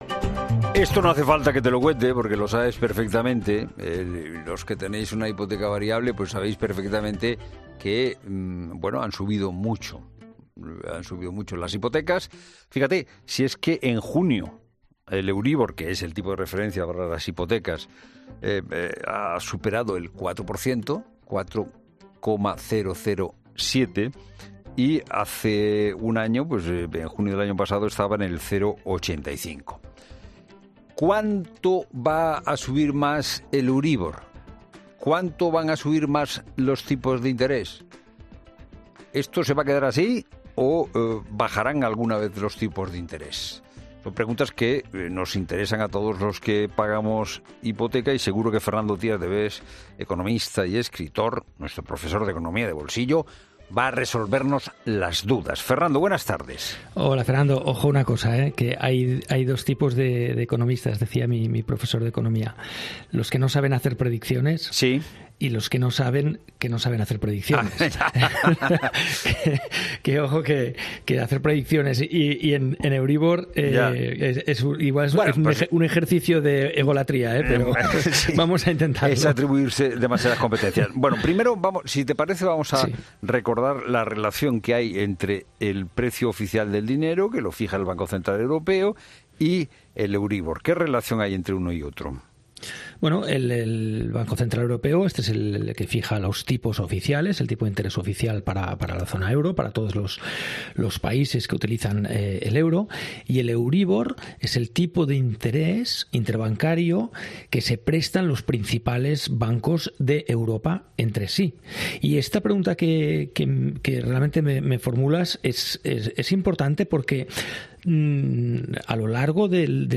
Sobre este asunto hemos hablado esta tarde con el economista y escritor, Fernando Trías de Bes.